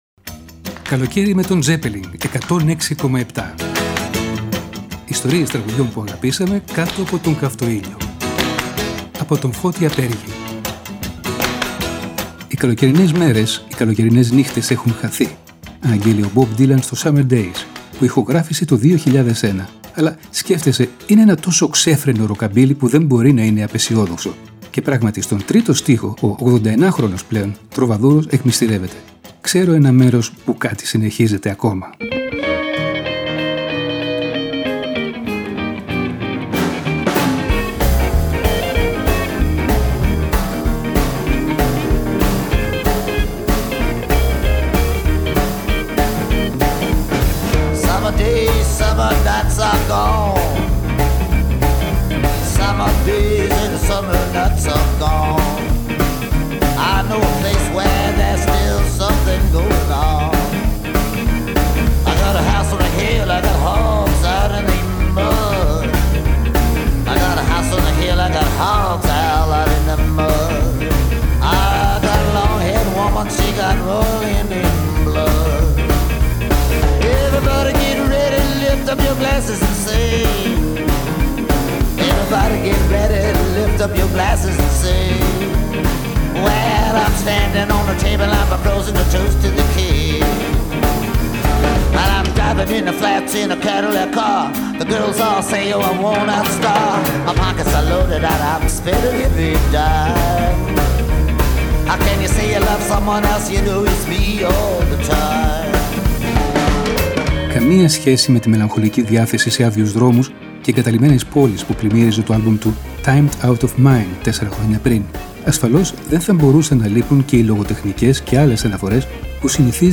Αλλά, σκέφτεσαι, είναι ένα τόσο ξέφρενο ροκαμπίλι, που δεν μπορεί να είναι απαισιόδοξο.